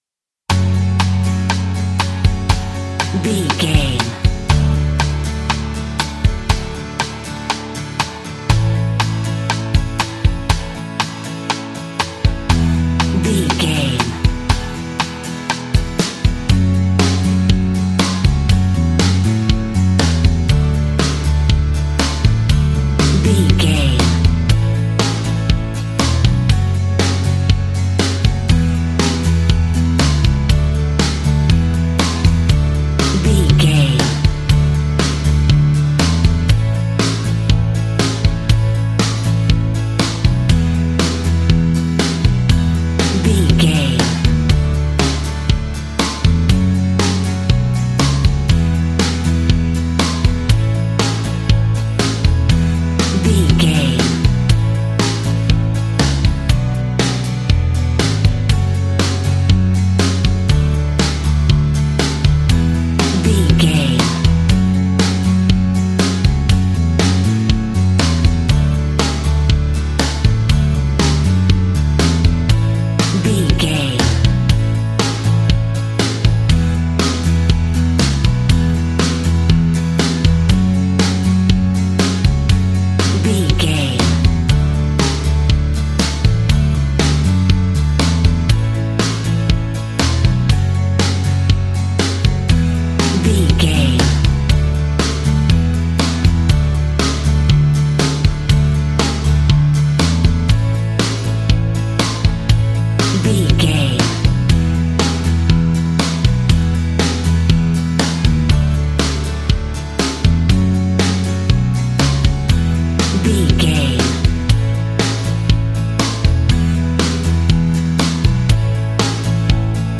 royalty free music
Ionian/Major
energetic
uplifting
instrumentals
guitars
bass
drums
organ